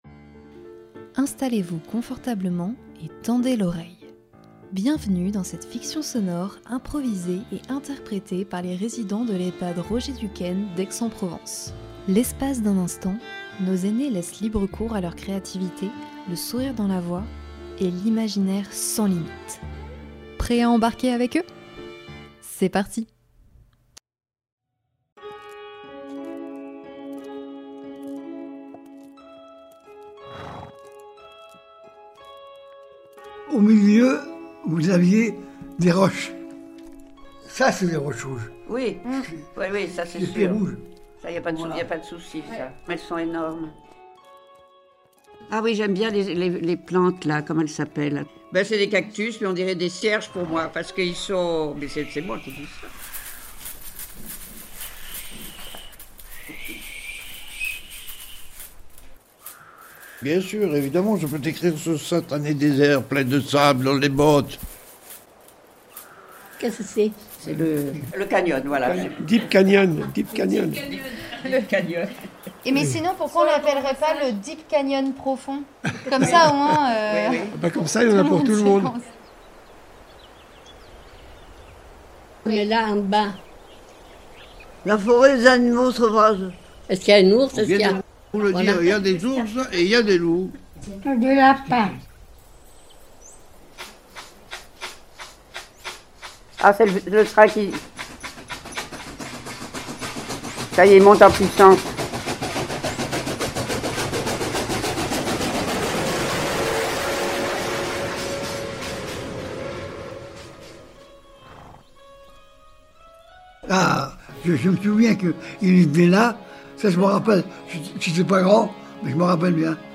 Une fiction sonore improvisée et jouée par les résidents de l’EHPAD Roger Duquesne d’Aix-en-Provence, au fil de 9 ateliers animés par l’association ECHOES entre janvier et mars 2025.